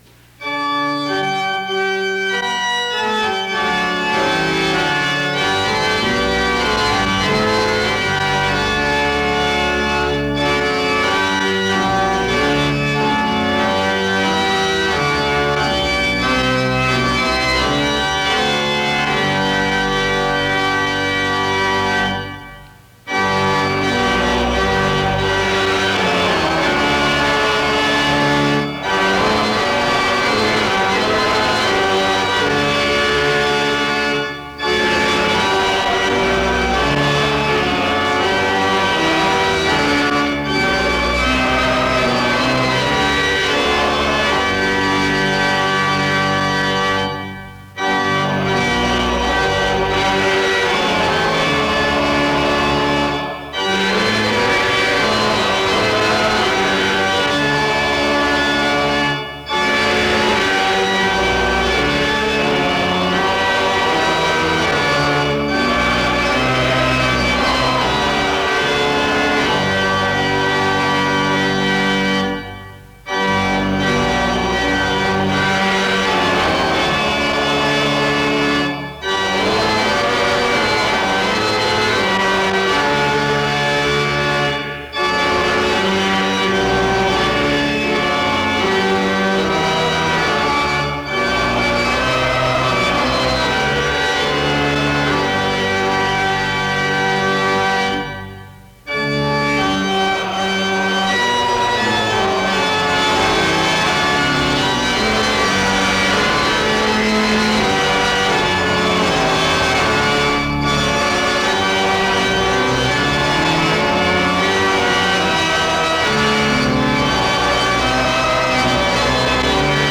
Download .mp3 Description The service opens with music from 0:00-2:12. A prayer is offered from 2:13-3:15. The chapel sings from 3:22-7:38. 1 Kings 19:3-12 is read from 7:52-9:56.
Music plays from 20:49-24:07.